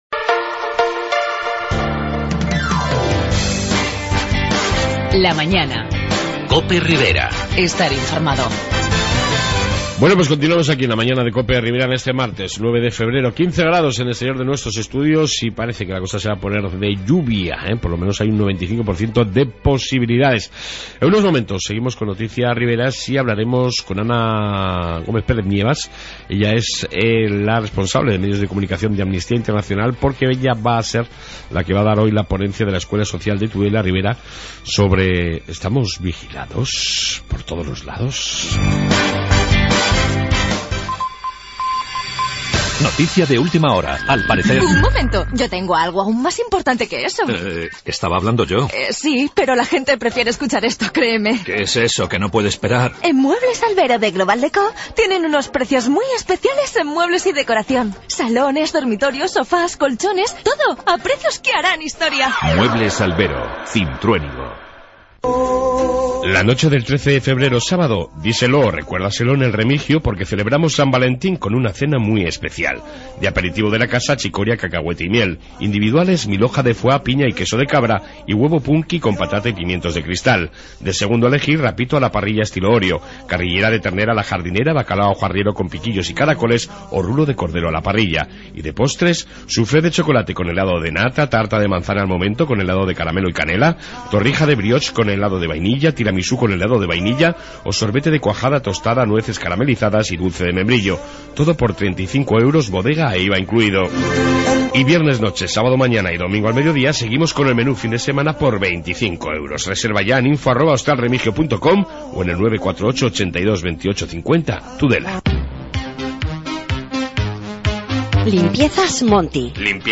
Audios Tudela